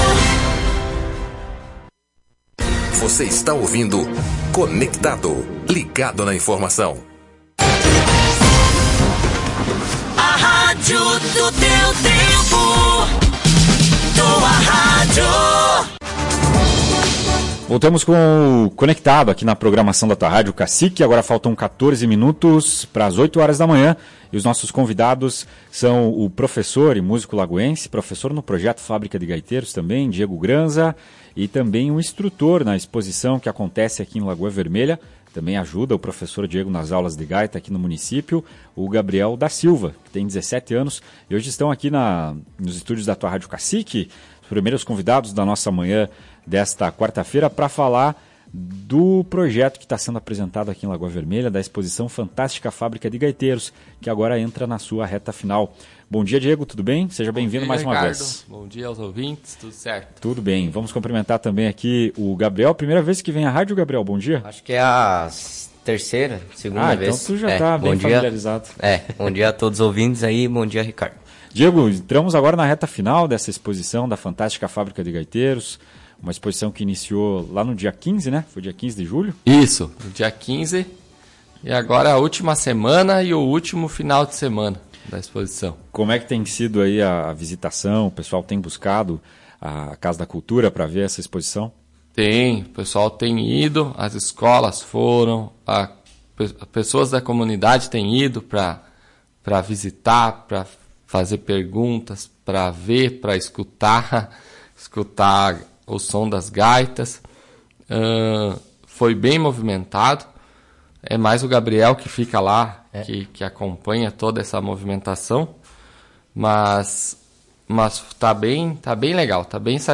Além das gaitas, fabricadas especialmente para alunos, a exposição apresenta peças interativas e uma banda animatrônica. Ouça a entrevista.